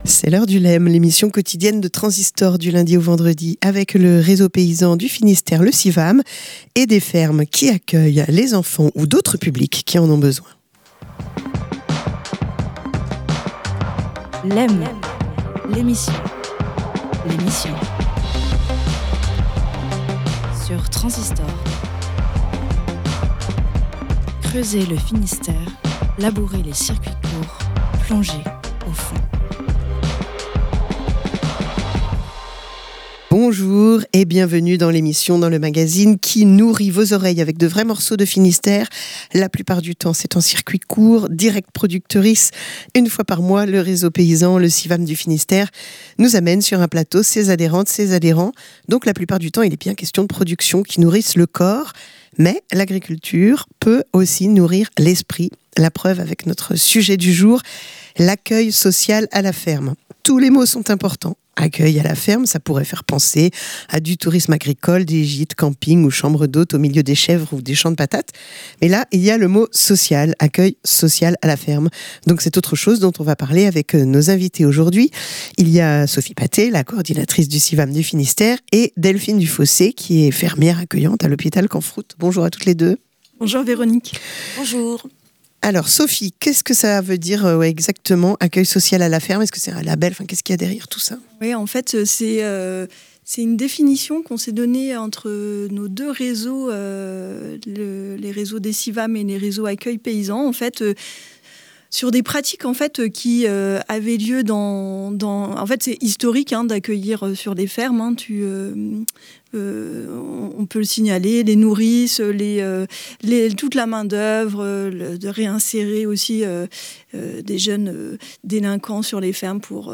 Une émission mensuelle de Lem, la quotidienne, réalisée en partenariat avec le réseau Civam du Finistère